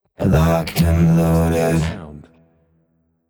“Locked and loaded” Clamor Sound Effect
Can also be used as a car sound and works as a Tesla LockChime sound for the Boombox.